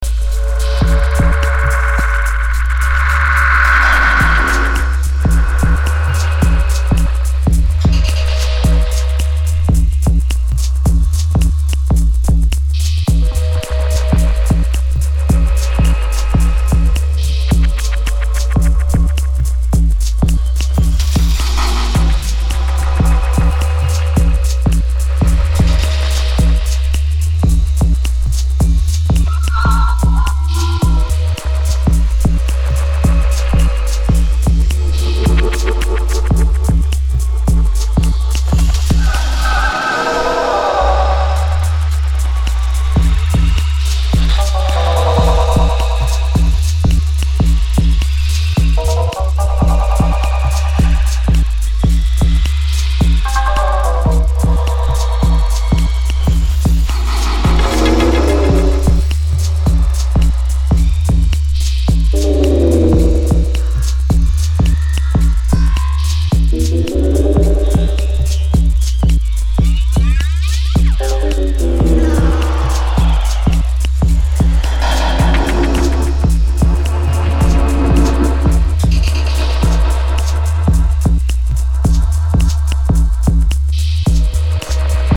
Killer dubbed out Ambient Drum & Bass/Electronica EP